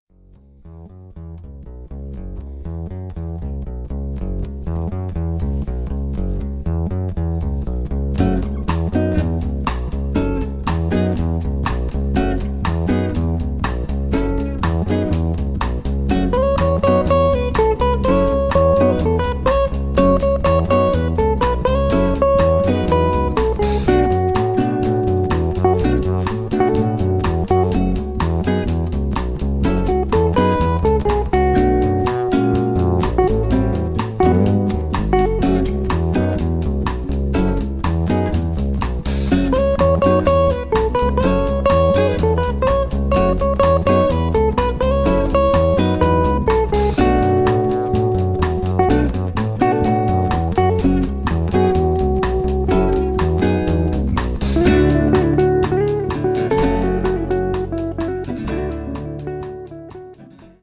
bass
guitar (solo)
drums
The whole without any special effect.